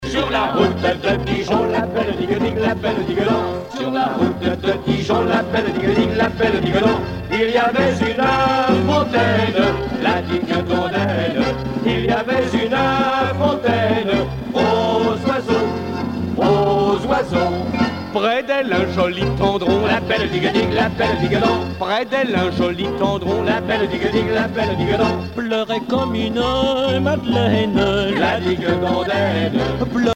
Fonction d'après l'analyste danse : marche
Genre strophique
Pièce musicale éditée